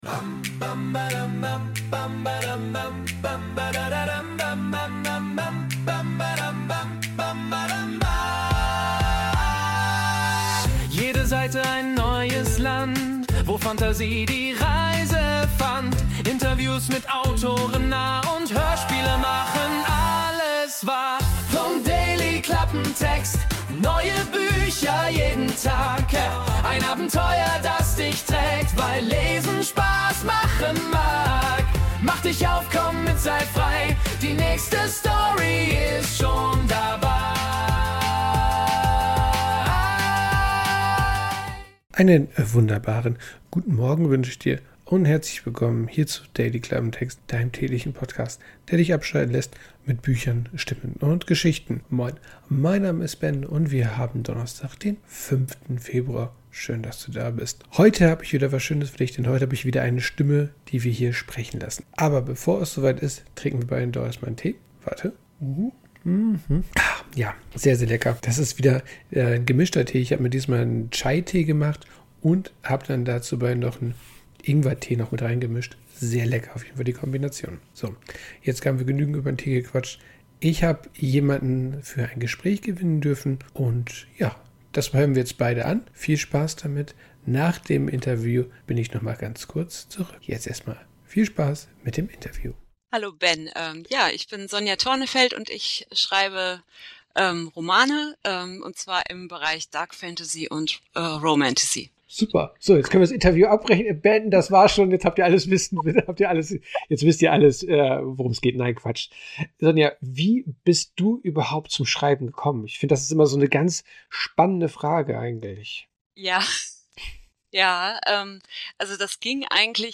Interview ~ Dailyklappentext Podcast